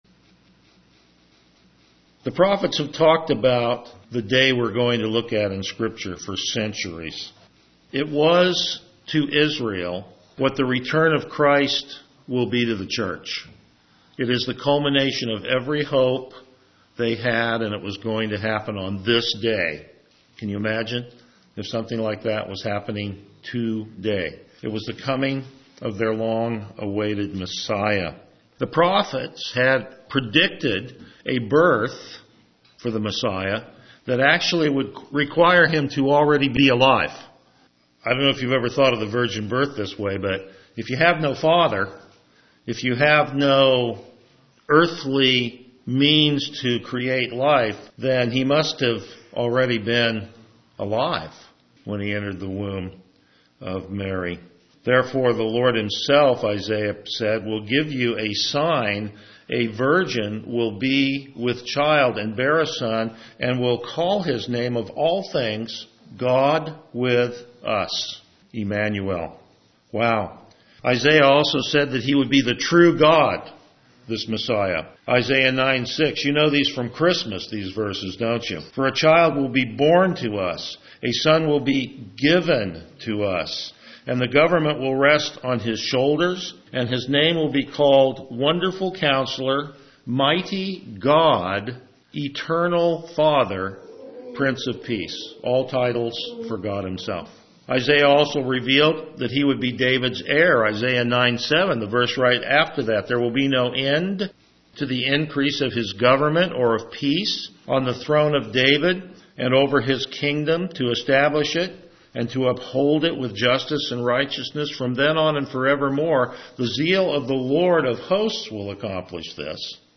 Passage: John 12:12-50 Service Type: Morning Worship